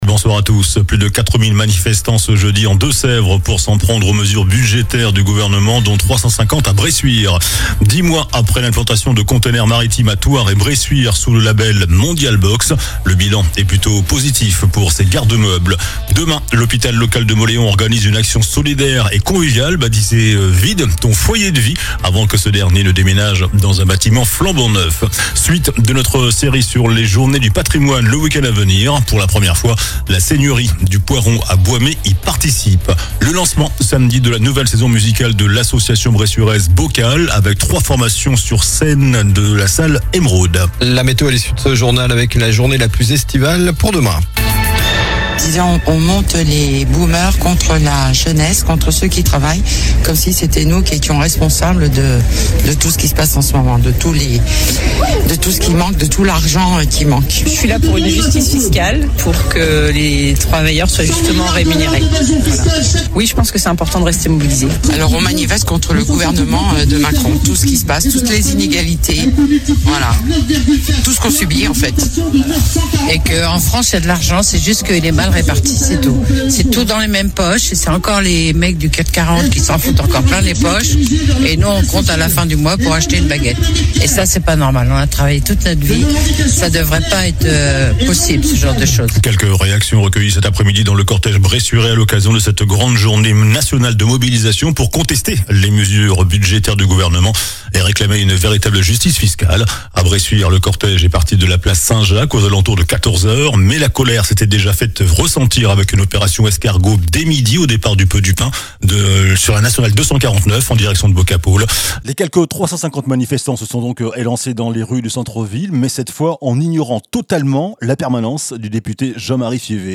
JOURNAL DU JEUDI 18 SEPTEMBRE ( SOIR )